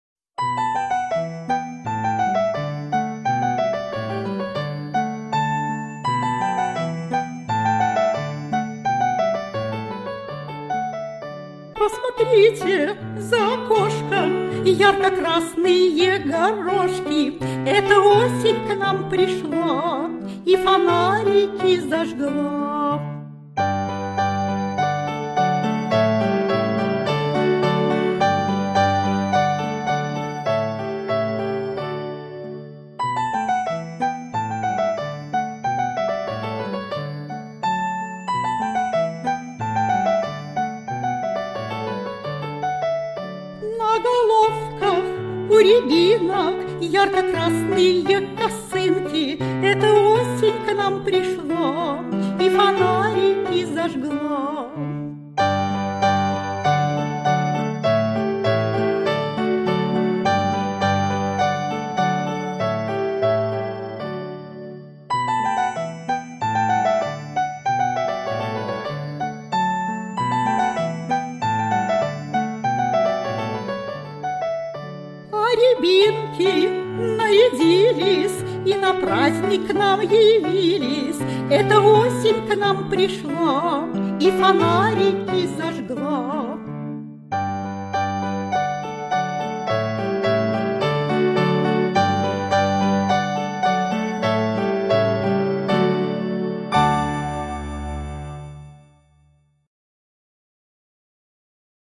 фонограмму (плюс) детской песни